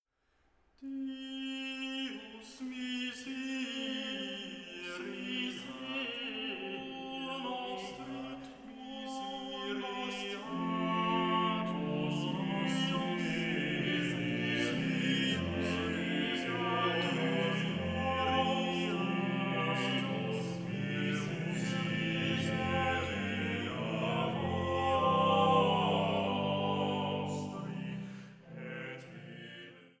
6- bis 7-stimmige Motetten der Cantiones Sacrae I